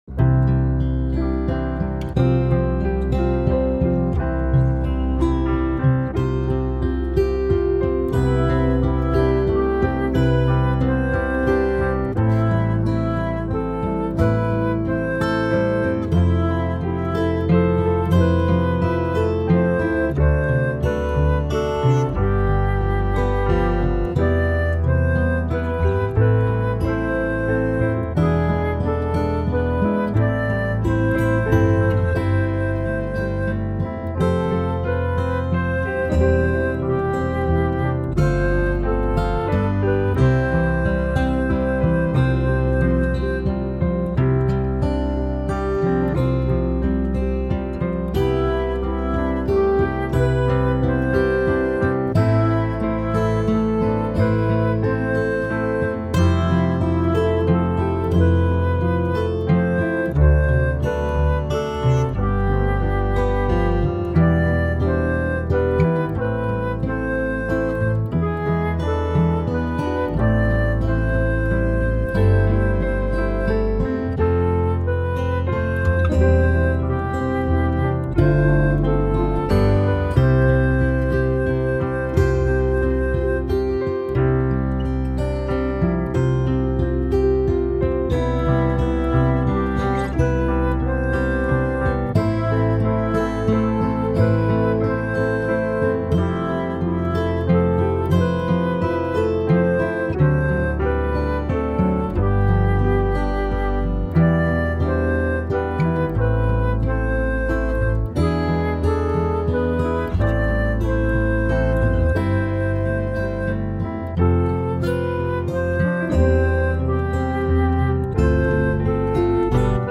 This is my BIAB backing.